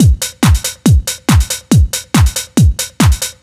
NRG 4 On The Floor 006.wav